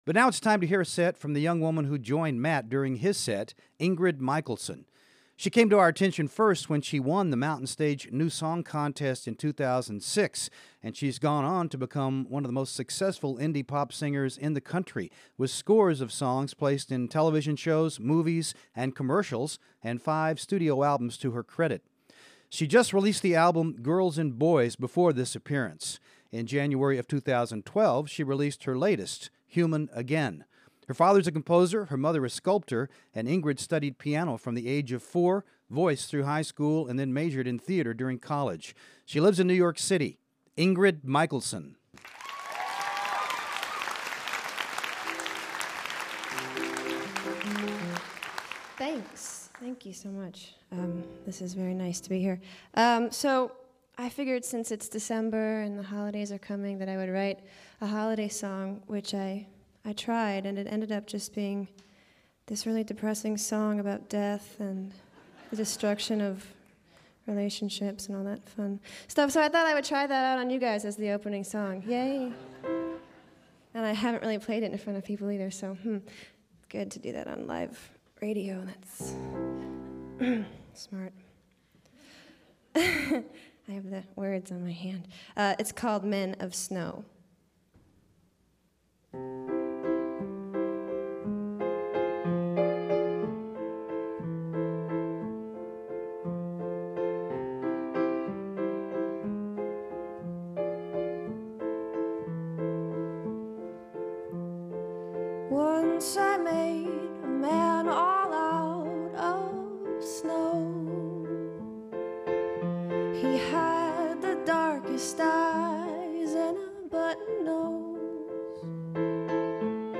The singer-songwriter plays sweet folk-pop